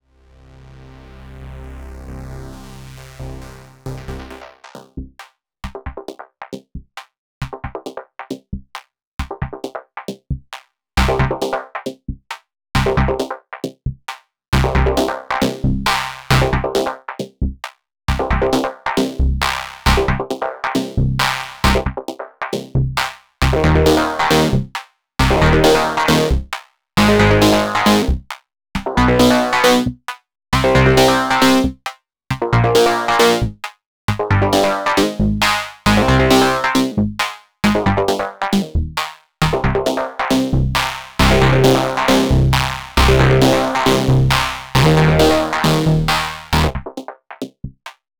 3 saws from Hydrasynth through OT, 8 Thru with bandpass filter, 2 resonances, decay/vol control with crossfader. Mono.